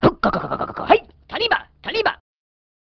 Escucha la voz de un Jawa